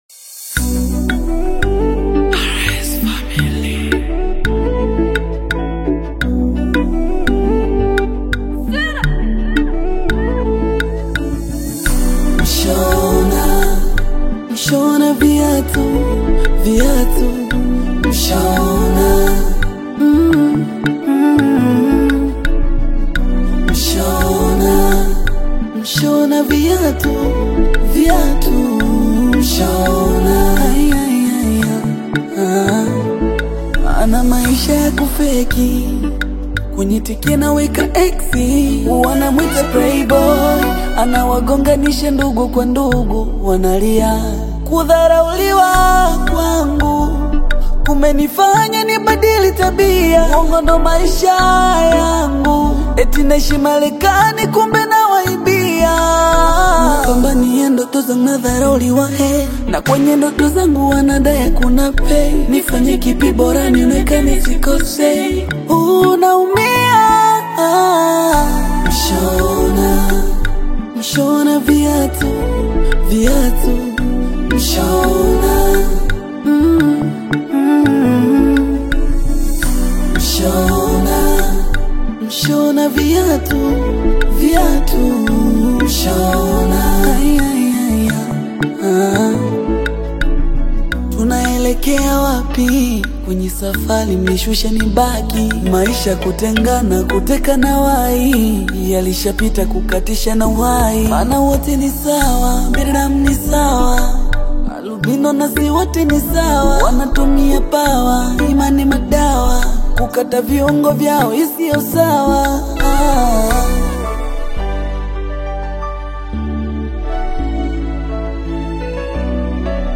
vocal delivery is filled with raw emotion